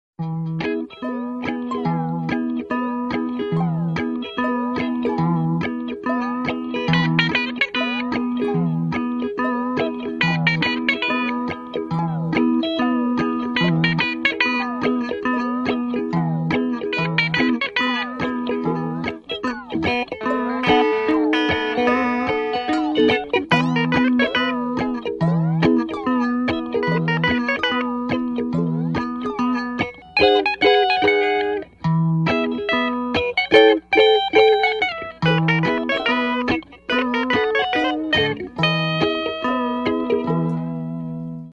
I hate the death sound it’s so loud💀